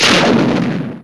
RocketRelease.wav